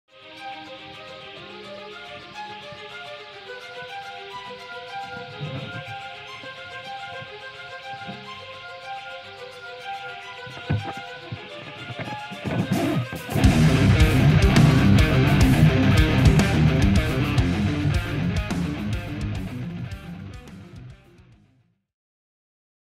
Выдернул шум из начала ролика.